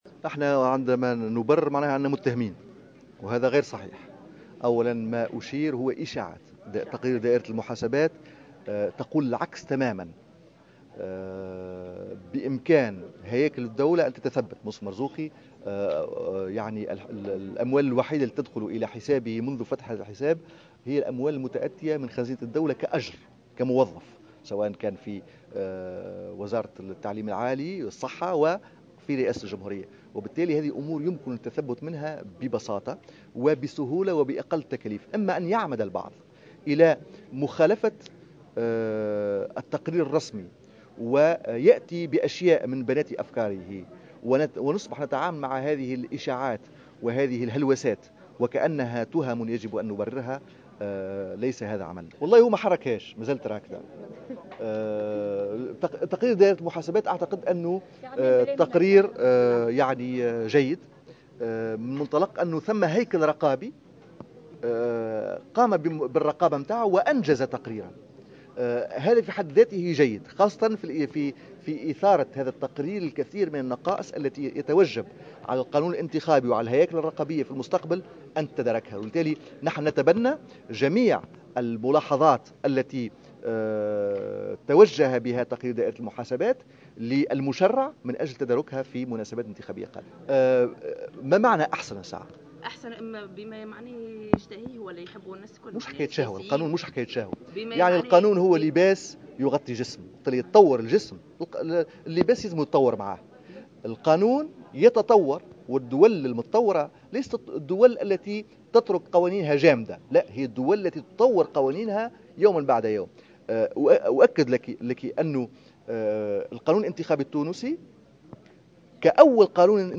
أكد القيادي في حراك شعب المواطنين عدنان منصر، في تصريح لمراسلتنا على هامش ندوة عقدت اليوم الخميس لتقديم تقرير حول دائرة المحاسبات، أن المنصف المرزوقي سينهي قريبا الإجراءات لإيداع قضية بتهمة التزوير واستعمال مدلس ضد من سيكشف عنه البحث في نشر هذه المعلومات المدلسة بخصوص تقرير دائرة المحاسبات.